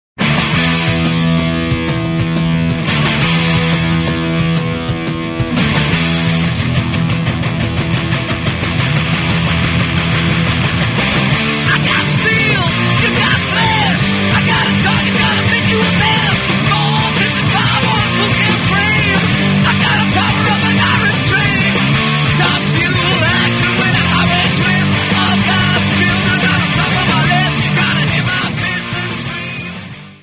To me it's pretty straightforward hard rock.